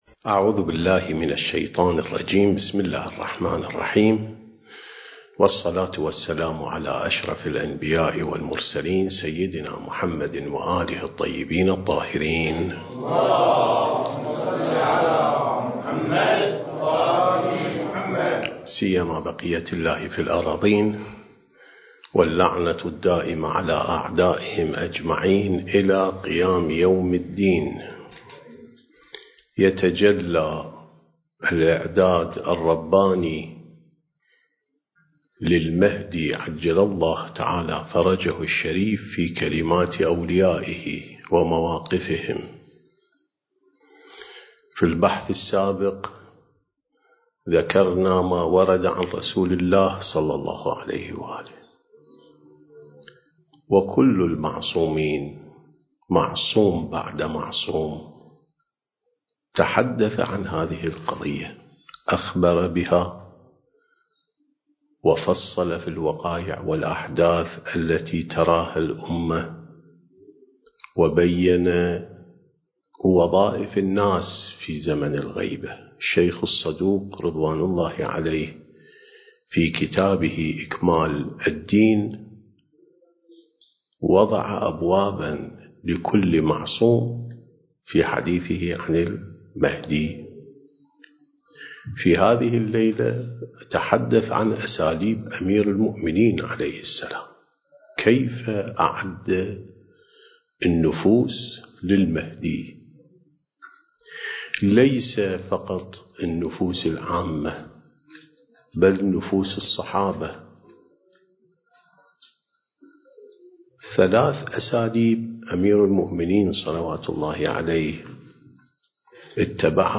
سلسلة محاضرات: الإعداد الربّاني للغيبة والظهور (6)